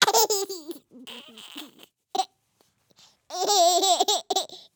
fixed baby sounds
tickle2.wav